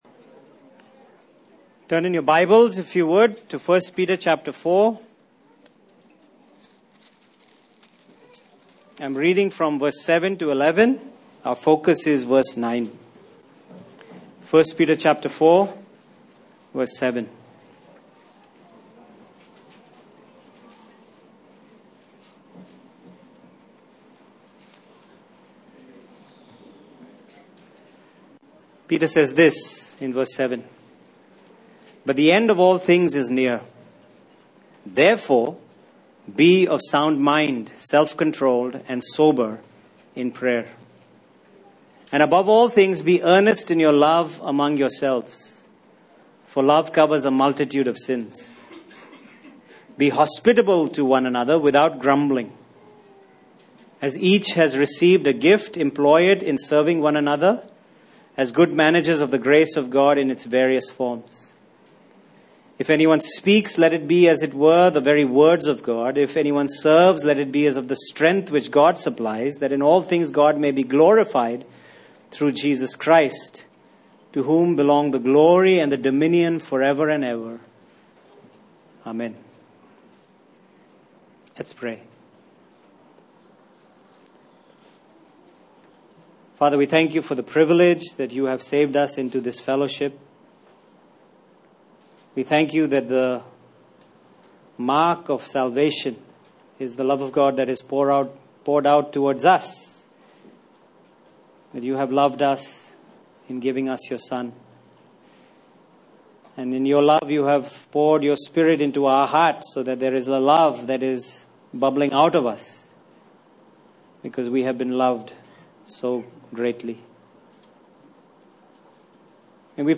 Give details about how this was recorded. Topical Passage: 1 Peter 4:9 Service Type: Sunday Morning Topics